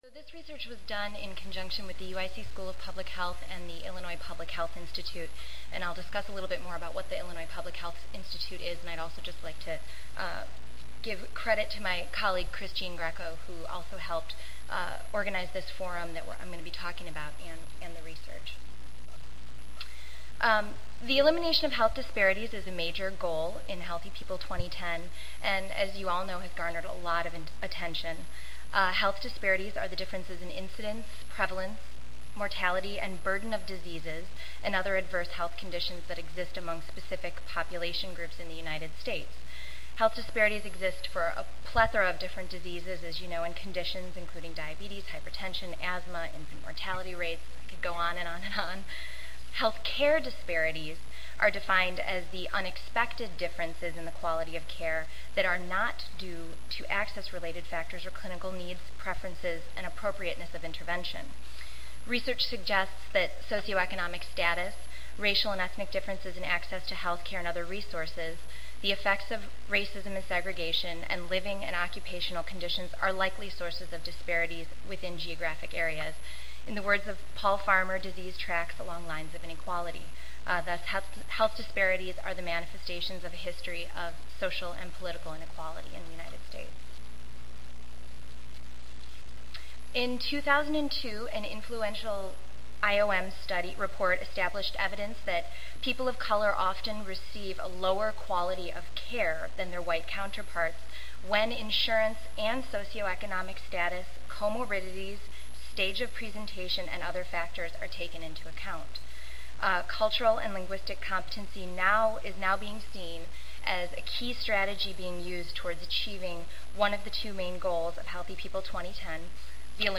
3034.0 Politics and Policy in Public Health Workforce Development Monday, November 5, 2007: 8:30 AM Oral This workshop will focus on public health policy on the workforce and the impact of cultural competence, health disparities and workforce certifications. Discussions will focus on cultural and linguistic competency and the impact of training, program development and implementation on the elimination of health disparities.
The third speaker will provide information on educational resources and information on interactive technology for rural communities and urban communities.